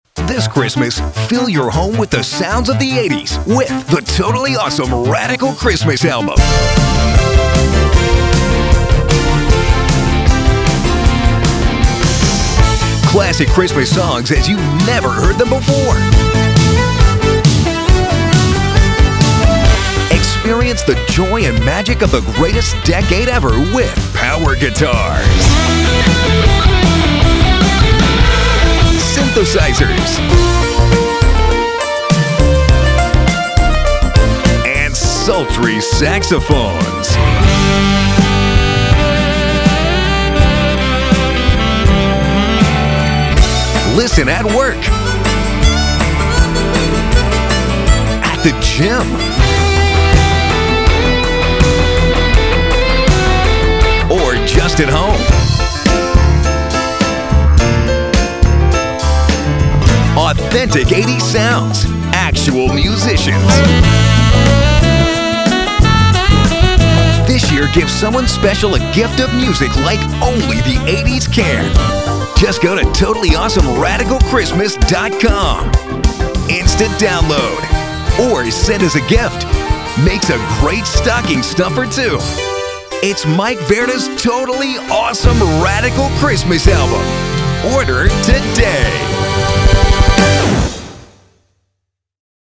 You'll love this collection of classic Christmas songs reimagined as 80's pop anthems! With power guitars, bright synthesizers, punchy drum machines, and silky saxophones, every track will have you smiling, laughing, and dancing your way through the holidays.
radio_ad.mp3